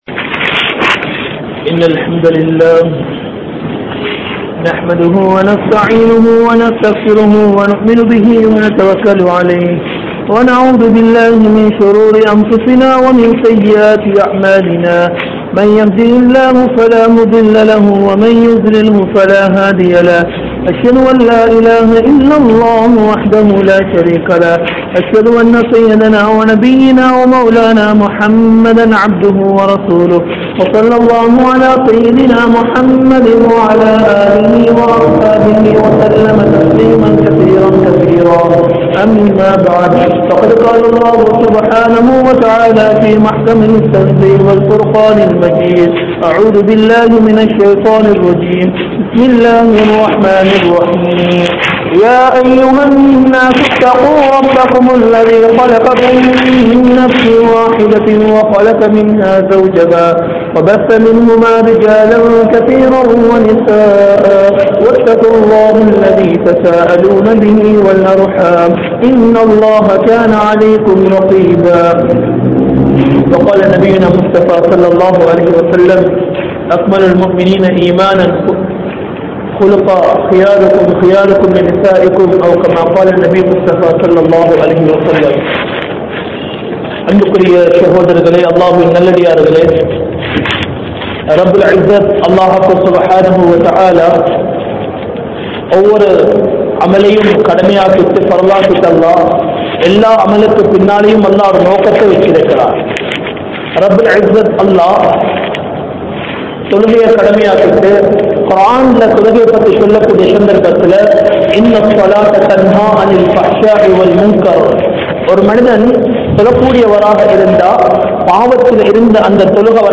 Alahaana Kudumba Vaalkai (அழகான குடும்ப வாழ்க்கை) | Audio Bayans | All Ceylon Muslim Youth Community | Addalaichenai
Colombo 04, Majma Ul Khairah Jumua Masjith (Nimal Road)